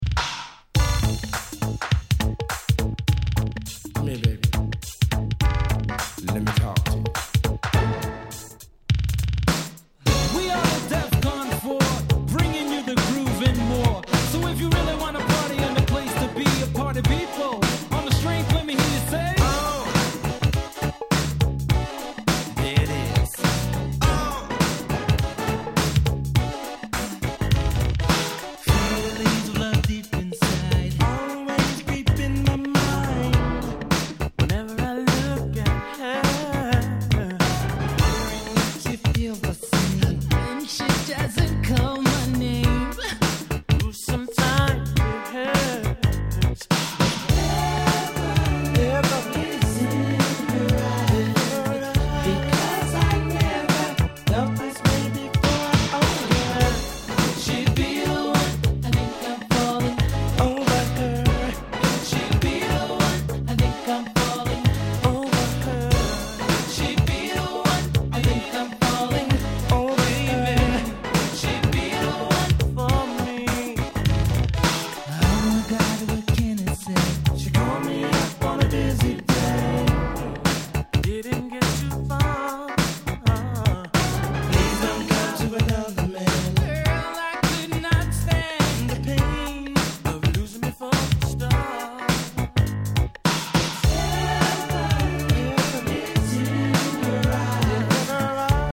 90' Nice New Jack Swing/R&B LP !!
シングルカットされたA-3を始め、ハネハネなNice New Jack Swing盛り沢山！